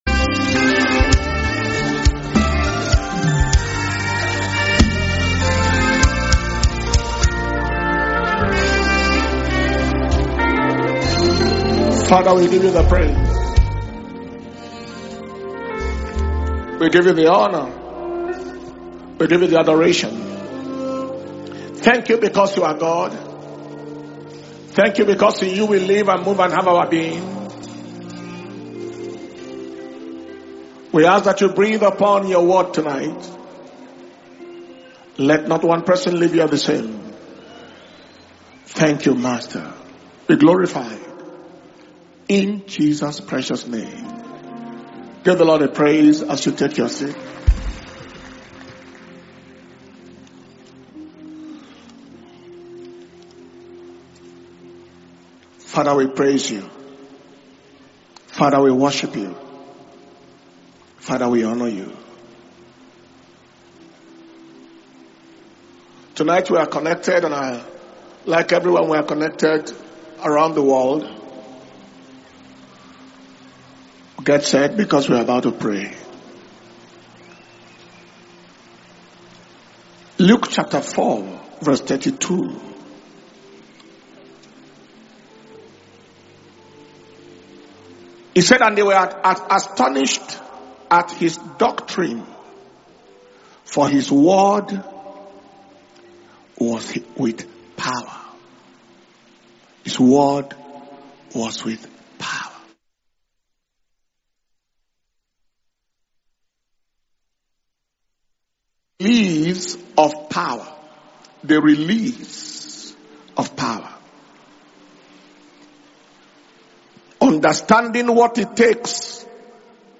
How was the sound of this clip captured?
Power Communion Service